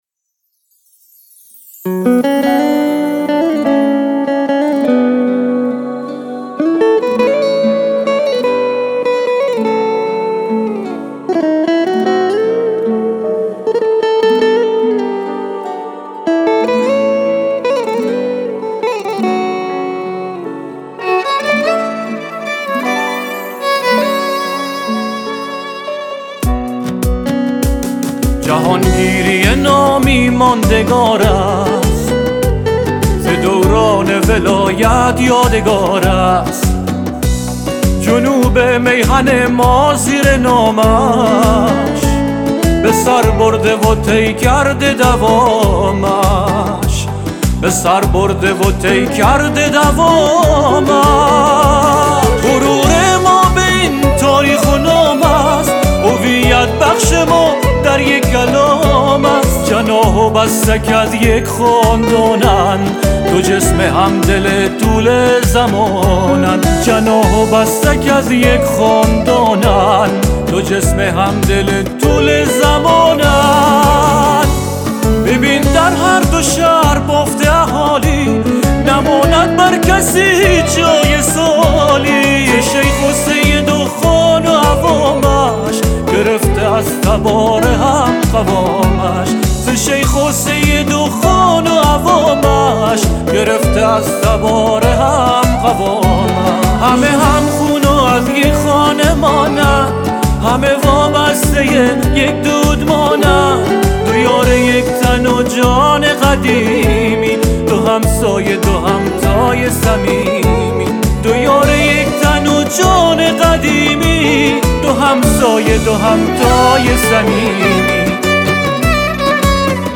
بستکی